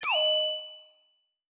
Holographic UI Sounds 106.wav